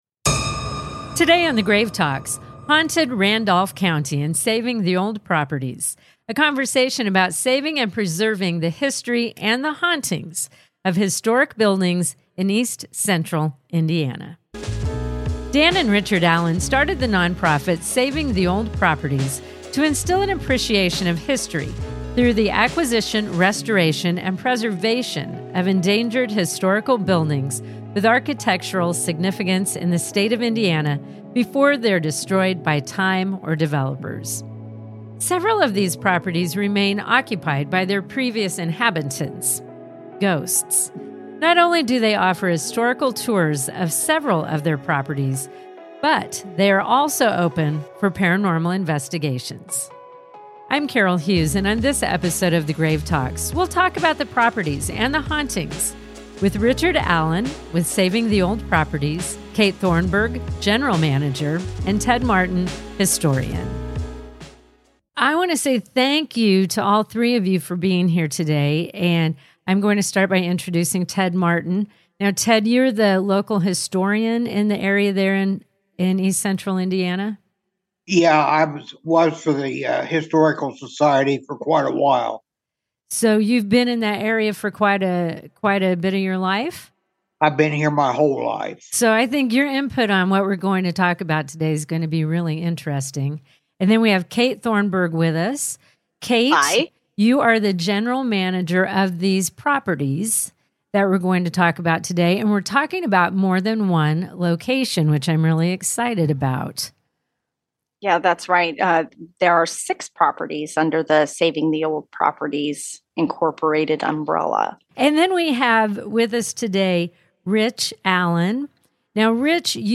From shadow figures and strange sounds to the emotional weight that lingers in historic spaces, this conversation explores where preservation meets the paranormal—and how history and hauntings often occupy the same foundation.